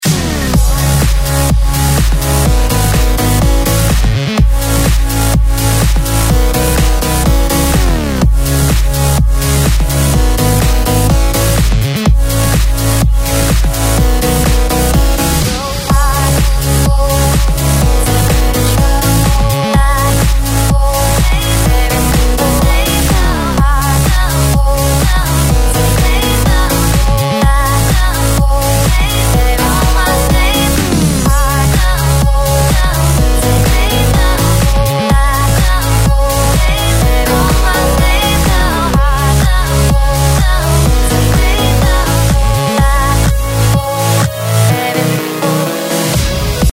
Электронная музыка